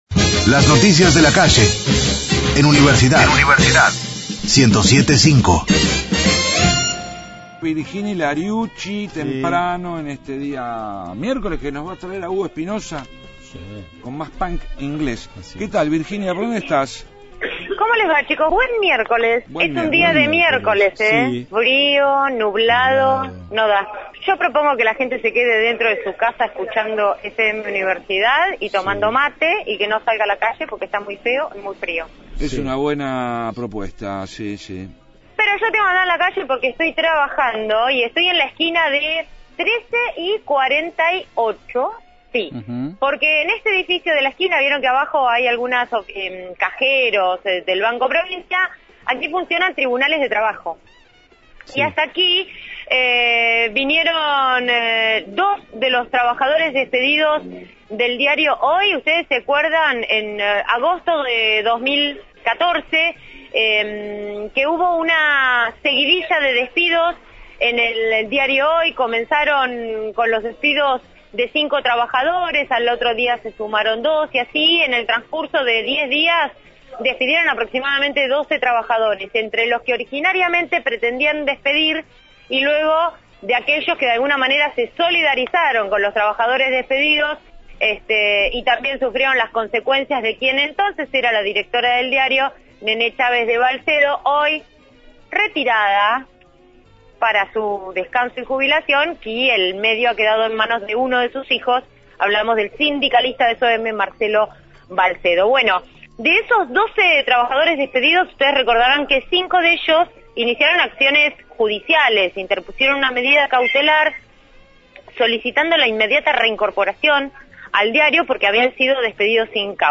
Móvil/ Suspensión de la audiencia por la reincorporación de los trabajadores del Hoy – Radio Universidad
desde el Ministerio de Trabajo, en 13 y 48